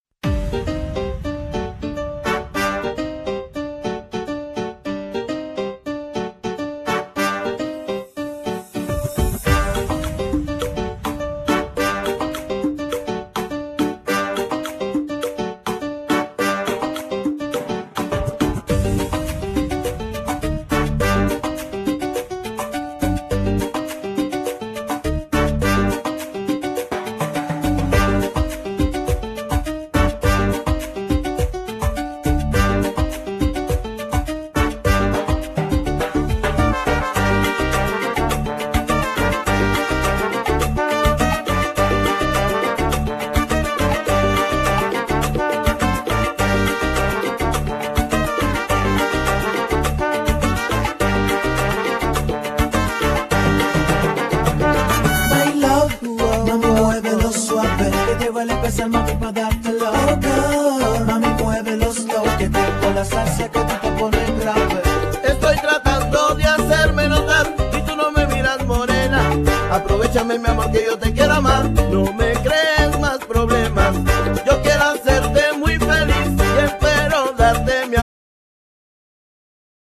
Genere : Pop latino